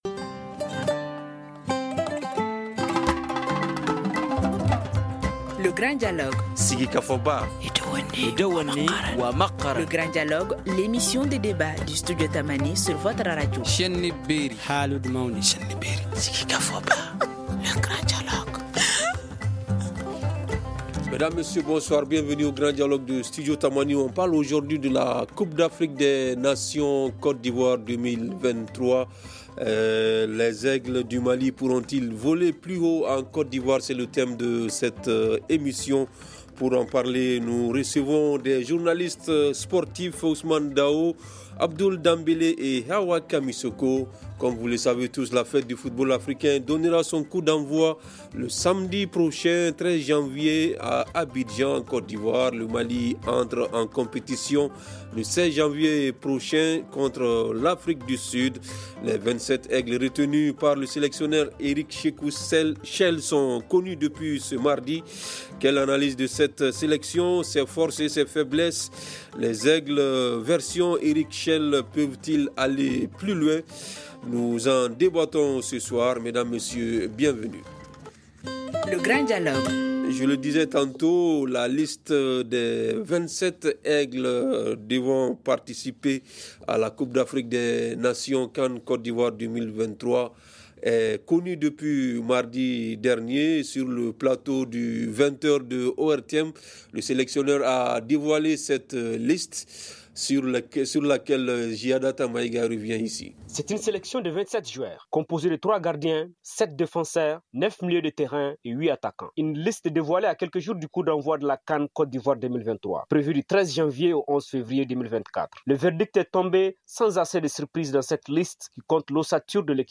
Nous en débattons aujourd’hui avec nos invités :